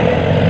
[WAV] Tank Audio
tank_sa_124.wav